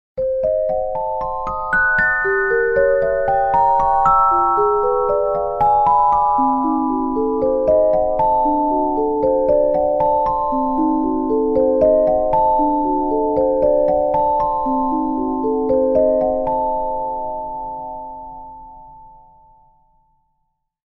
Genres: Sound Logo